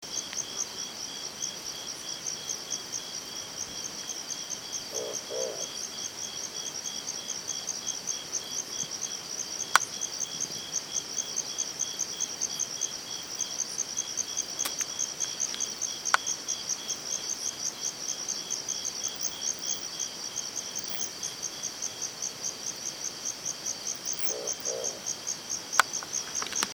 Chaco Owl (Strix chacoensis)
Location or protected area: Parque Provincial Chancaní
Detailed location: Sector de acampe
Condition: Wild
Certainty: Recorded vocal